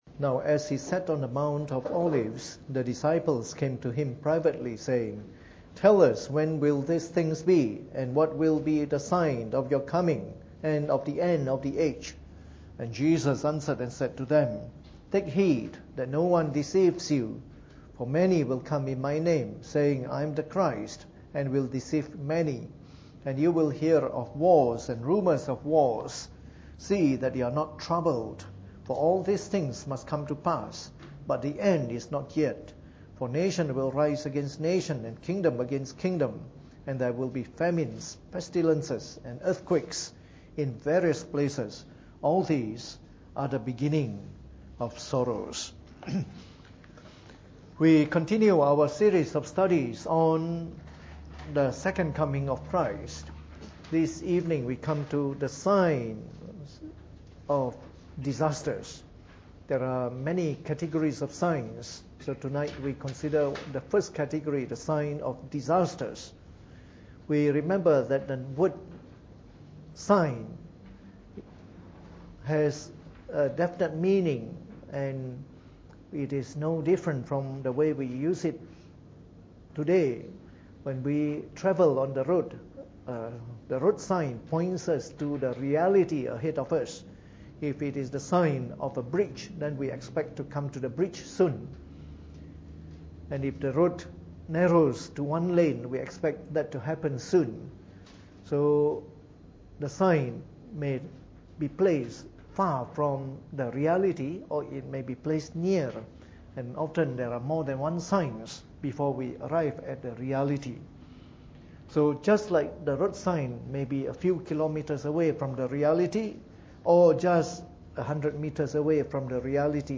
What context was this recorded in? Preached on the 8th of October 2014 during the Bible Study, from our series of talks on Eschatology.